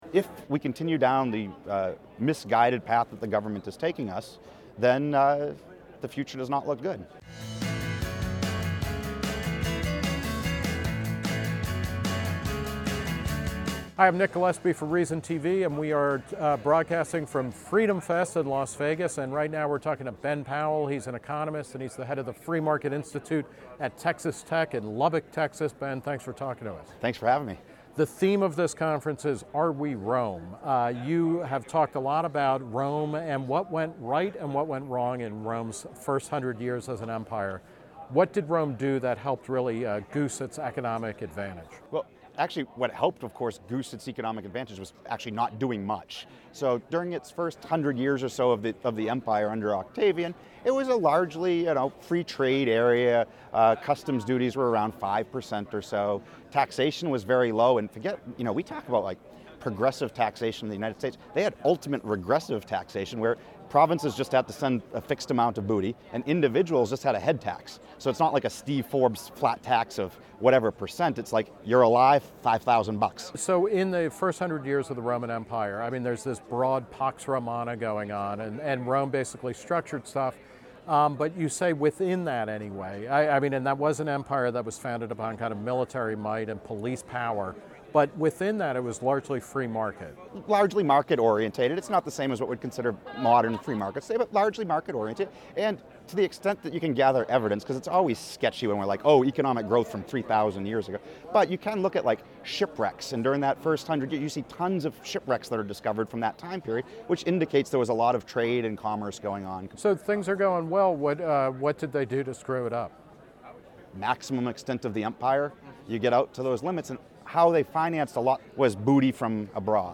Held each July in Las Vegas, Freedom Fest is attended by around 2,000 limited-government enthusiasts and libertarians. Reason TV spoke with over two dozen speakers and attendees and will be releasing interviews over the coming weeks.